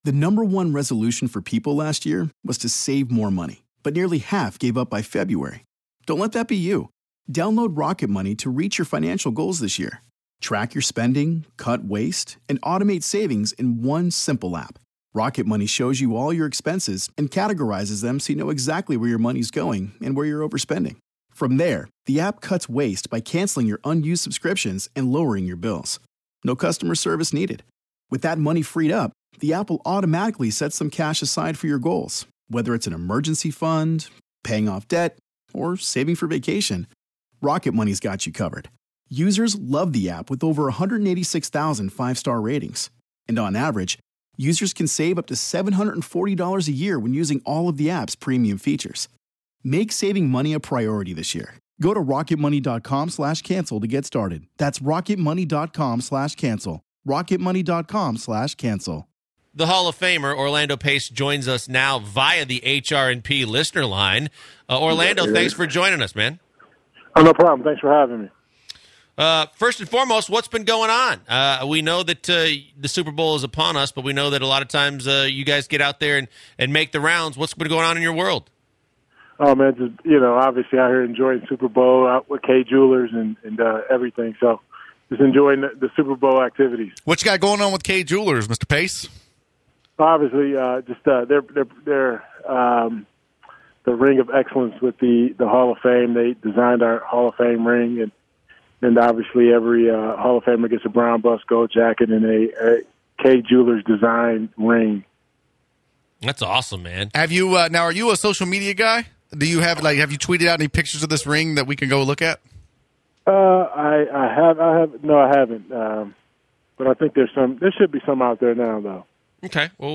Orlando Pace Interview